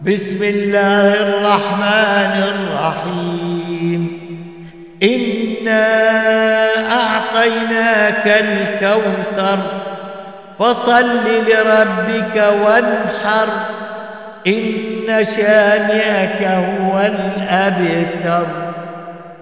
دروس التجويد وتلاوات